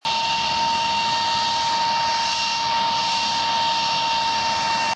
goblin.mp3